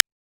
• Smooth Trap Acoustic Snare D Key 12.wav
Royality free snare one shot tuned to the D note.
smooth-trap-acoustic-snare-d-key-12-R4g.wav